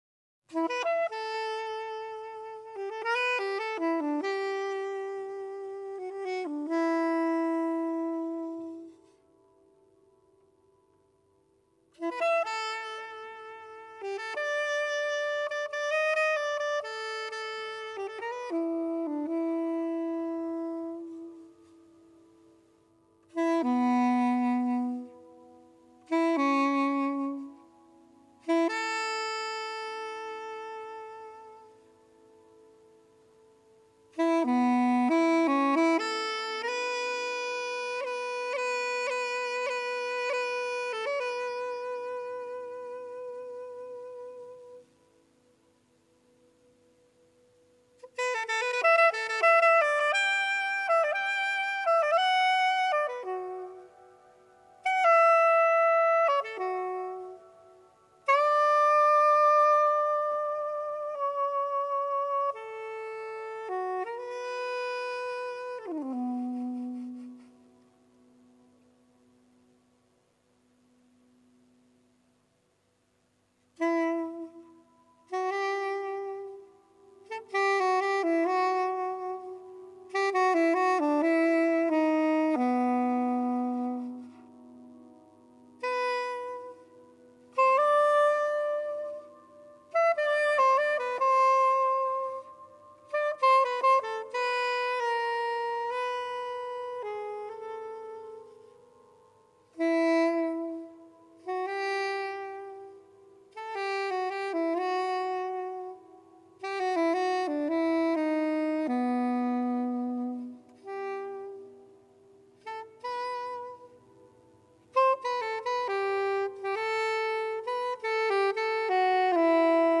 pianoforte
sax
in concerto
GenereJazz